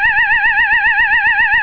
SIRENA ELECTRÓNICA - 49 SONIDOS/3 AVISOS
Serie: EMERGENCIA- EVACUACION
100dB